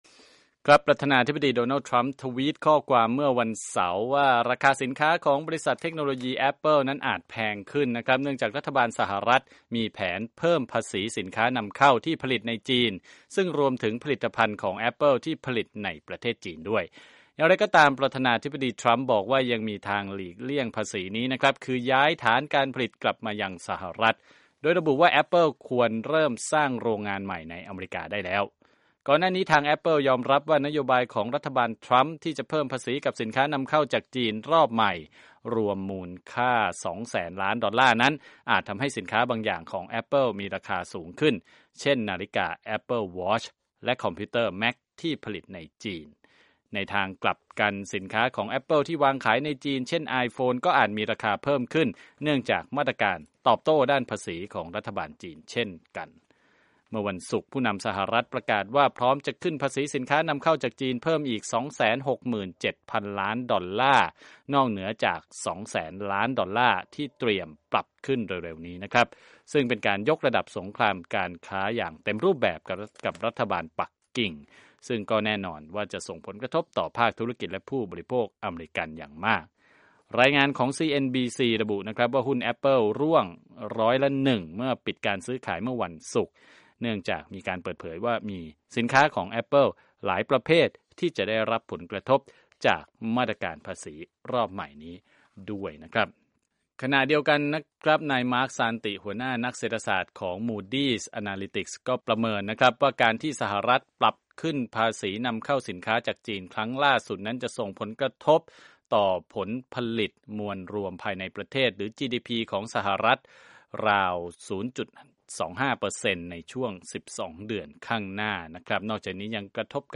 ข่าวธุรกิจ 9/9/2018